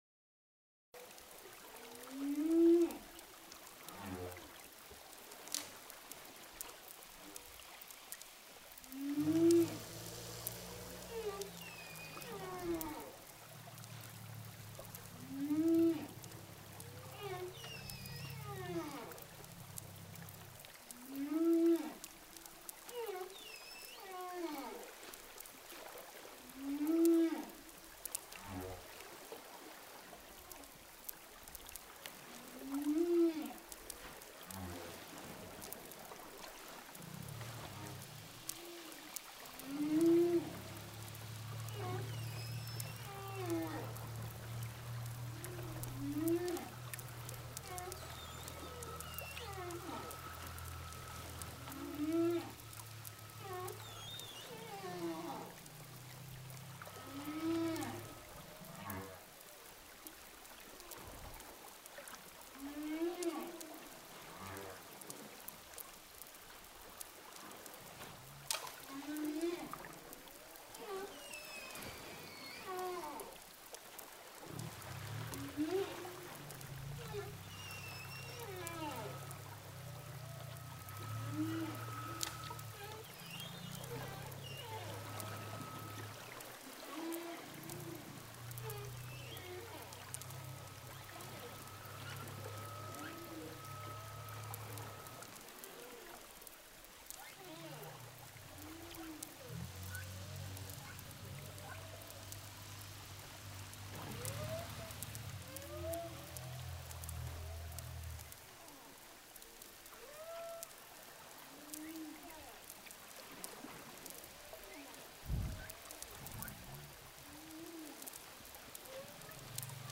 Baleas.mp3